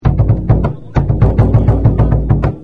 バテリア＆サンバ楽器
surdo.mp3